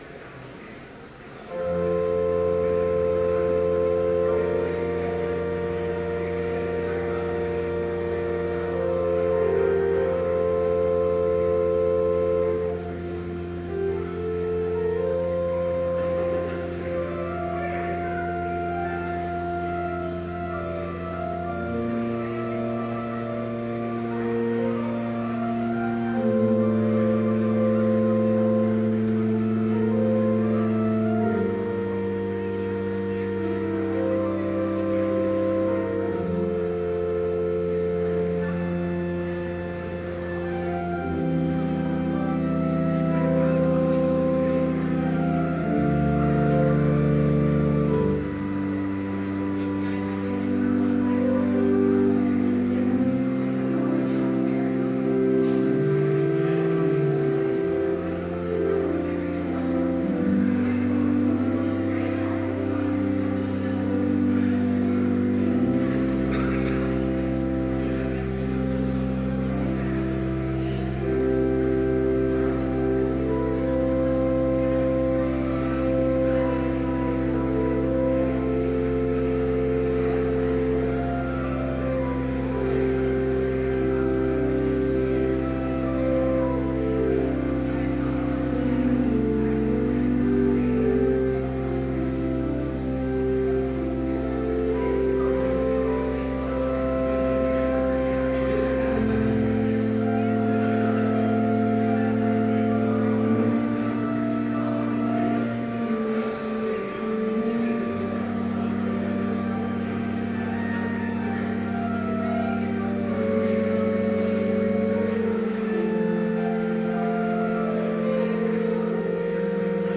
Complete service audio for Chapel - November 7, 2013
Prelude Hymn 421, vv. 1-3, We Are Called by One Vocation Reading: Galatians 6:9-10 Homily Prayer Hymn 421, vv. 4 & 5, Judge not hastily… Benediction Postlude